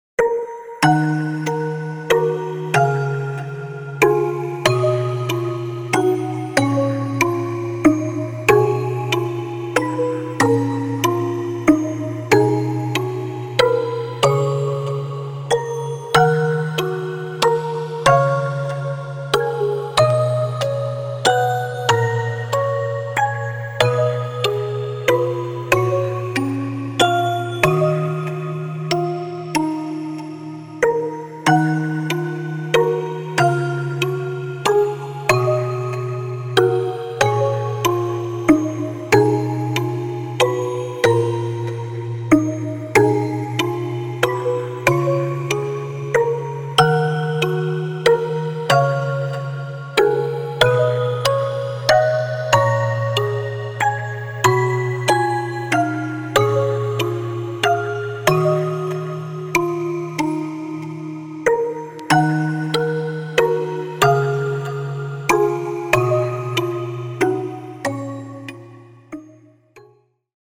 フリーBGM イベントシーン 切ない・悲しい
動画制作・ゲーム制作・配信など、さまざまなシーンでご利用いただけるフリーBGMです。
フェードアウト版のmp3を、こちらのページにて無料で配布しています。